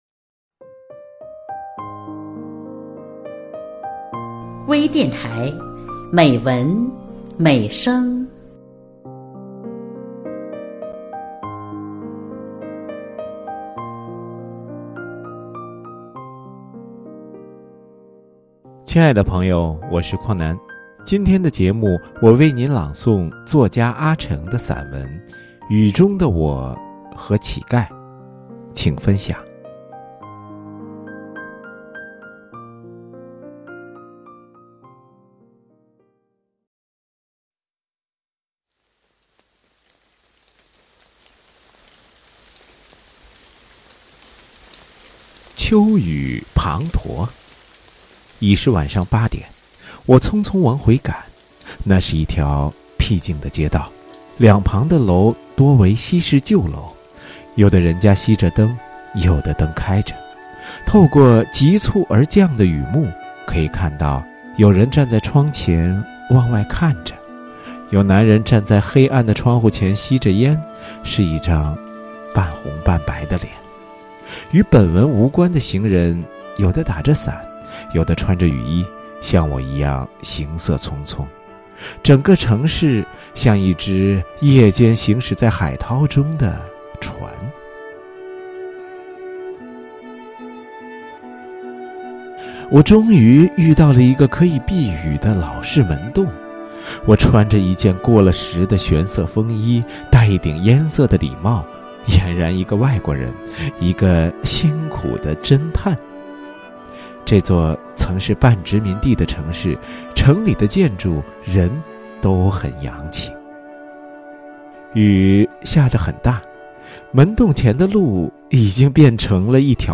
多彩美文  专业诵读
朗 诵 者) s8 j' y0 C; d- O% e6 z